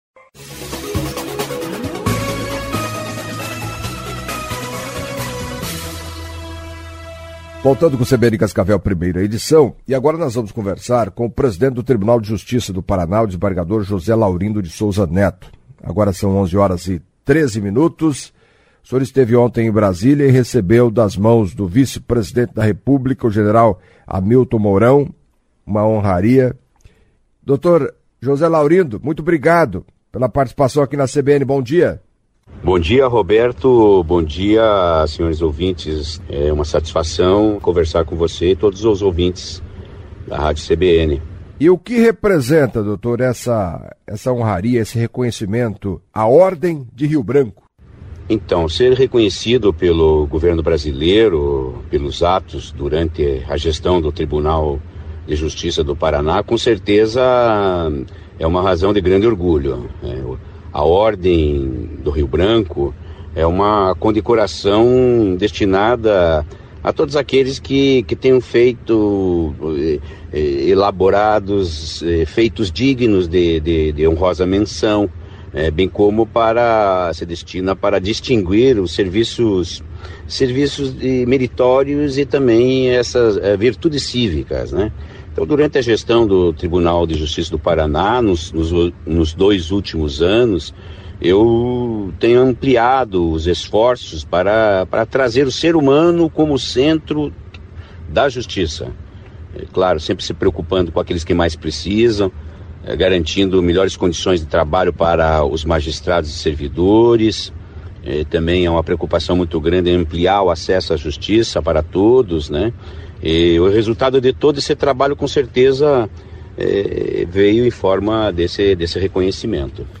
Em entrevista à CBN Cascavel nesta quinta-feira (08) o presidente do Tribunal de Justiça do Paraná, José Laurindo de Souza Netto, entre outros assuntos, falou da medalha Ordem de Rio Branco recebida nesta quarta-feira (7) no grau Grande Oficial. A entrega ao Desembargador Souza Netto foi feita pelo vice-presidente Hamilton Mourão, no Palácio Itamaraty, em Brasília.